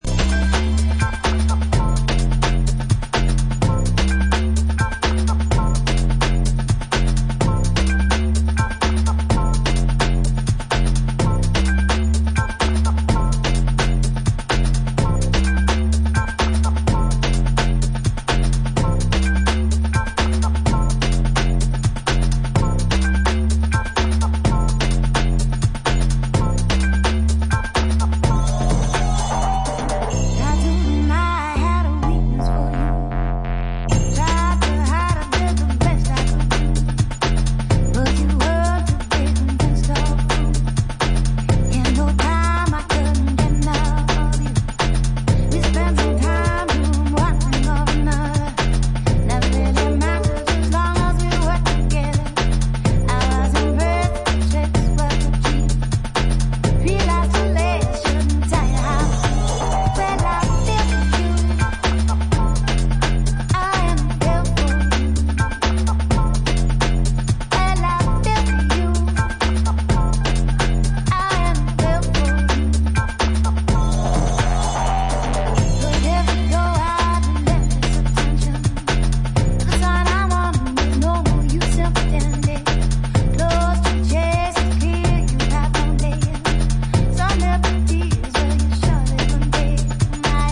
In their brand new subterranean Hackney studio
riffs, beats and ideas